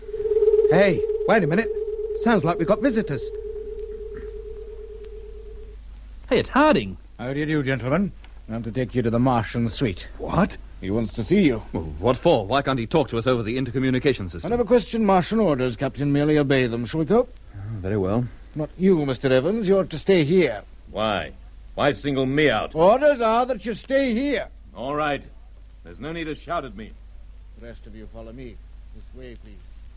Wanneer onze vrienden al een tijdje in hun verblijven aan boord van de asteroïde zitten, komt Harding ze halen voor een klein tochtje, maar Evans mag niet mee. In de Britse versie gaat dat wat onvriendelijk, terwijl de Nederlandse Harding kennelijk geen emoties kent.